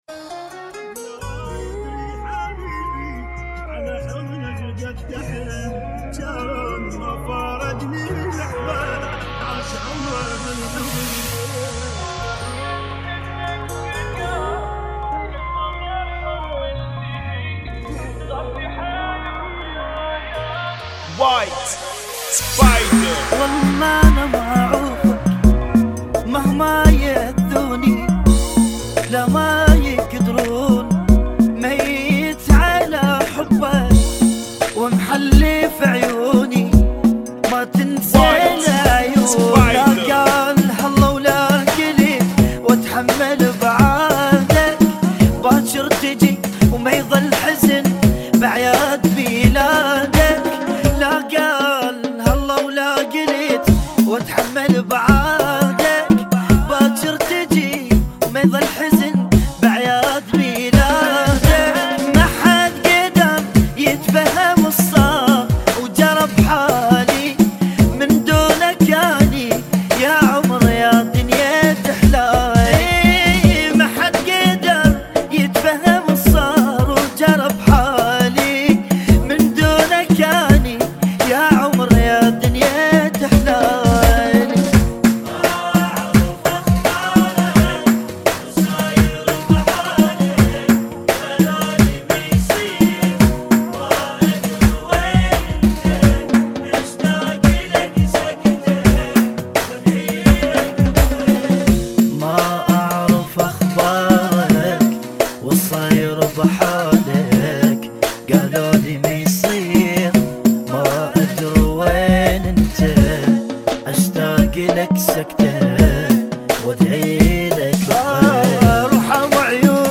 [ 85 Bpm ]
Remix